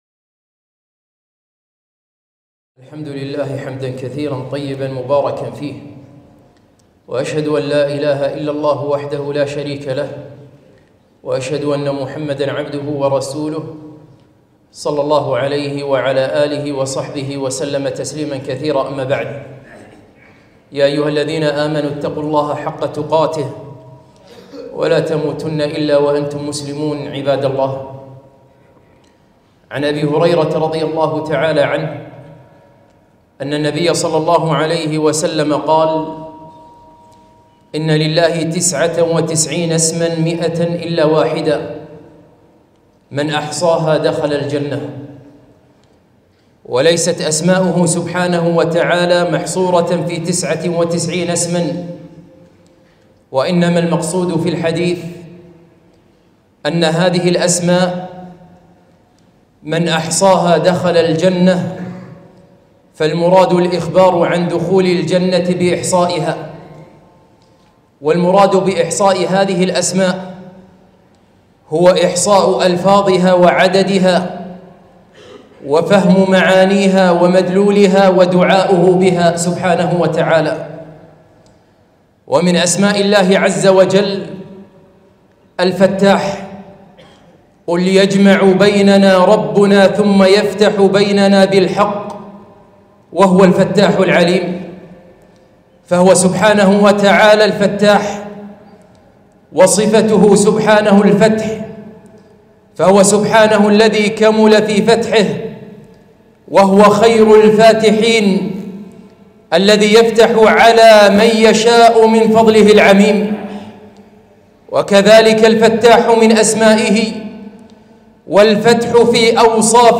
خطبة - اسم الله الفتاح